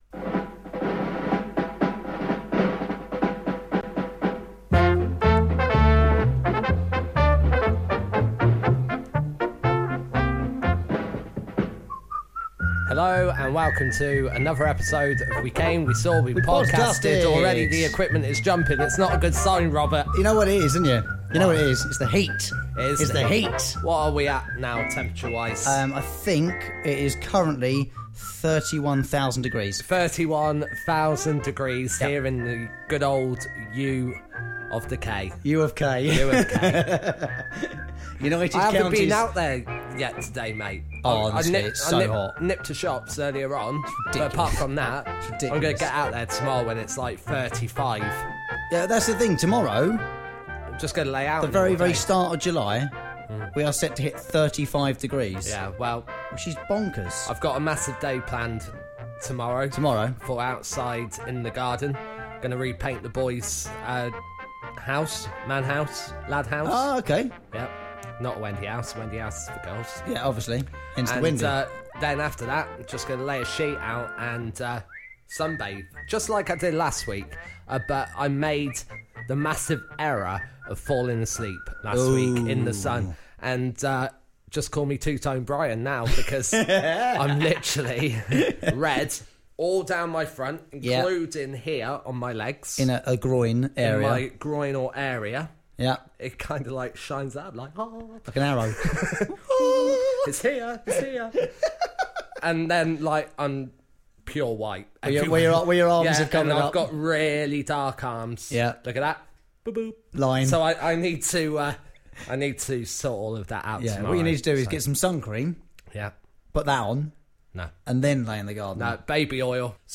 The Podcast was invaded by a Fly.